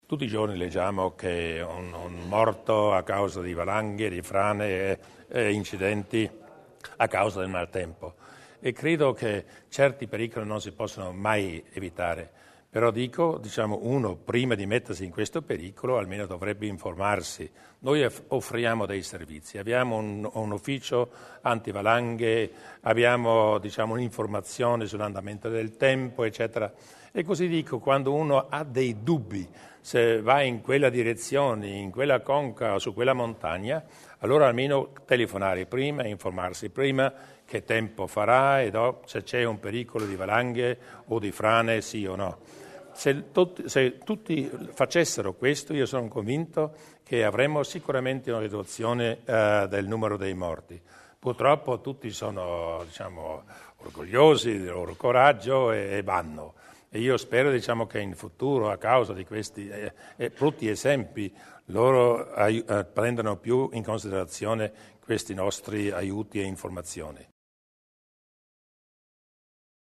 Un invito a tutti a prestare la massima attenzione al rischio valanghe, in particolare servendosi degli aggiornati e puntuali servizi di informazione della Provincia: lo ha rivolto oggi (6 dicembre) il presidente della Provincia Luis Durnwalder alla popolazione, riprendendo una sollecitazione della Giunta.